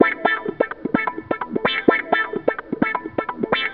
VEH1 Fx Loops 128 BPM
VEH1 FX Loop - 38.wav